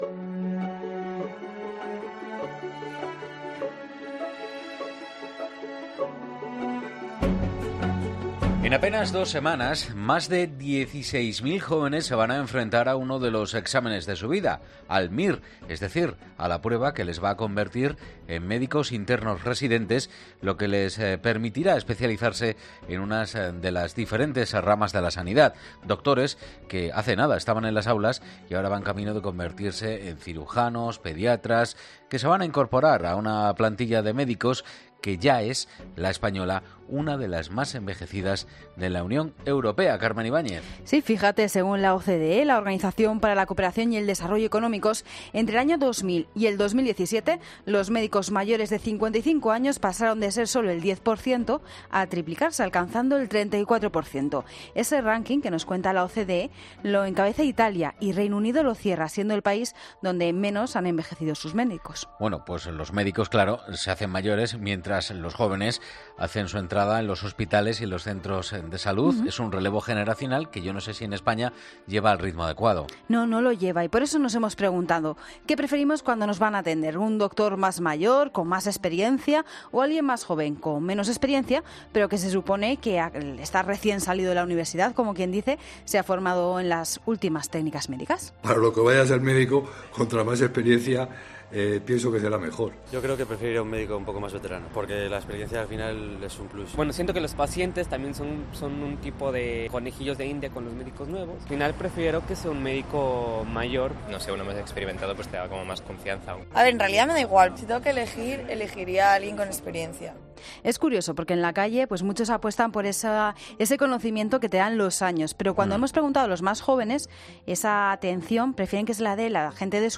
De hecho, en la Mañana de Fin de Semana de COPE hemos hablado con un joven que está estudiando el MIR, y aspira a convertirse en traumatólogo, que nos ha recordado que “la medicina no son solo conceptos teóricos, que obviamente son fundamentales, estudio y conocer las diferentes patologías, también requiere de otro tipo de habilidades como son la buena entrevista clínica o la intuición”.